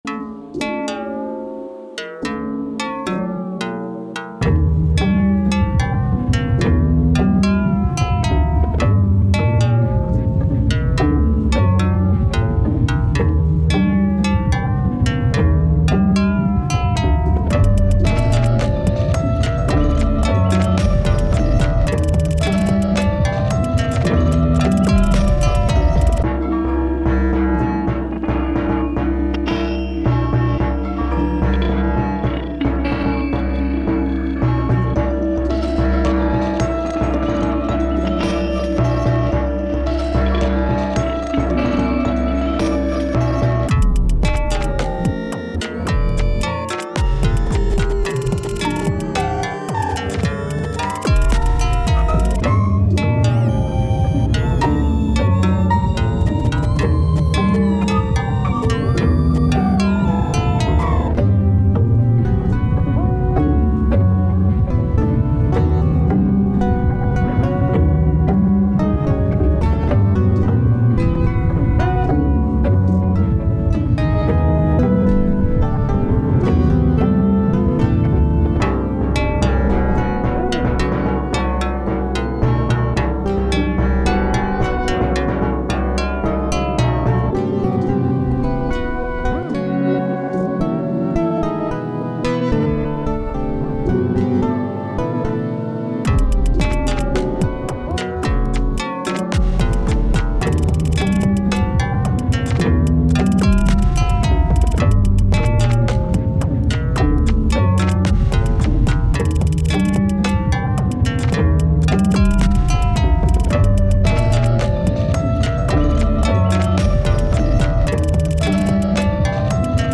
発音後すぐにピッチが変化するサウンドを多用することでヘンテコな雰囲気を出している。